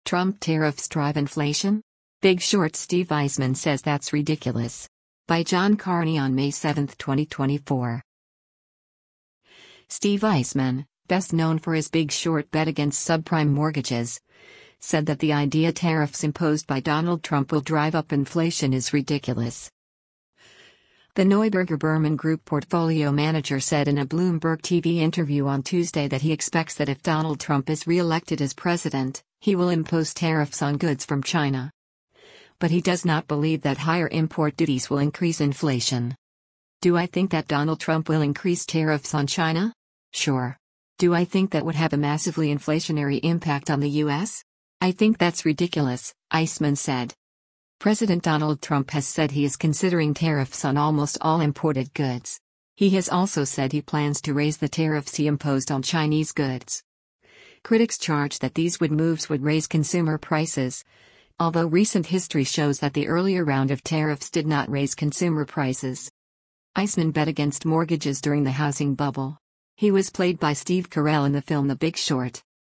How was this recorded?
The Neuberger Berman Group portfolio manager said in a Bloomberg TV interview on Tuesday that he expects that if Donald Trump is re-elected as president, he will impose tariffs on goods from China.